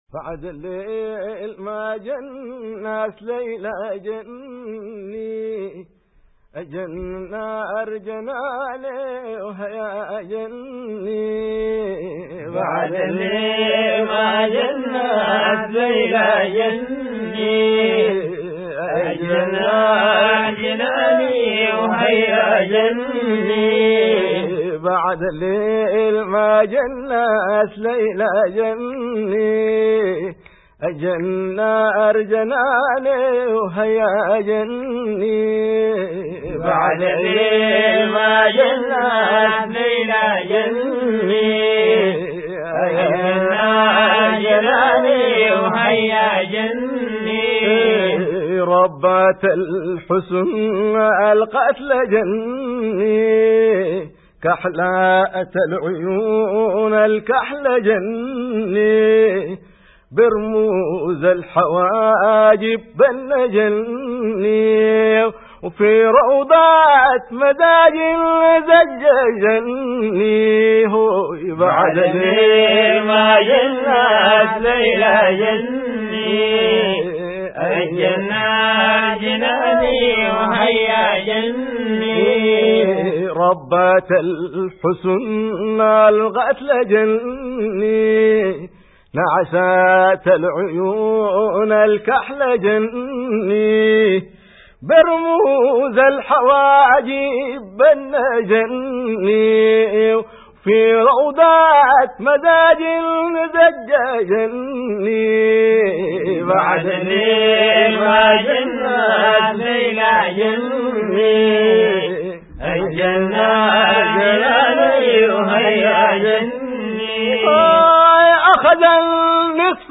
أدب المدائح